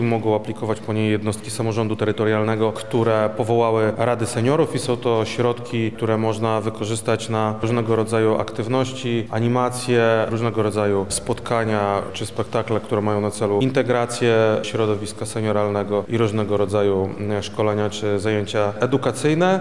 Krzysztof Komorski-mówi Krzysztof Komorski, Wojewoda Lubelski.